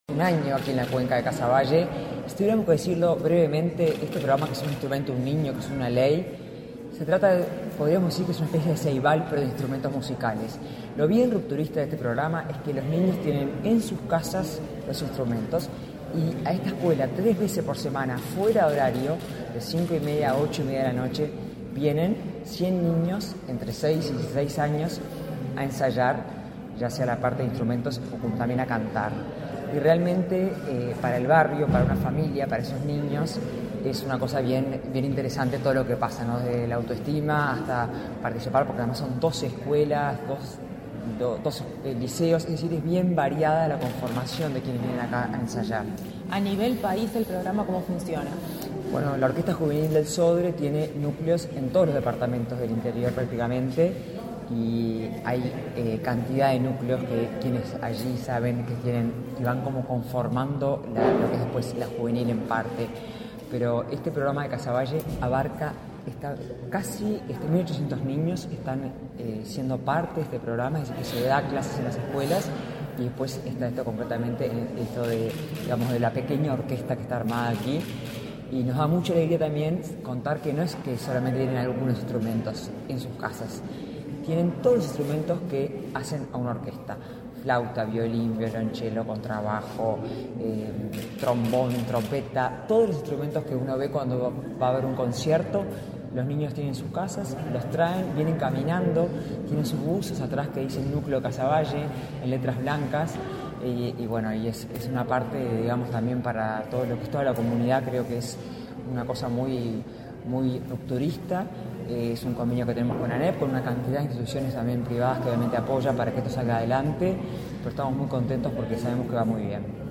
Entrevista a la presidenta del Sodre, Adela Dubra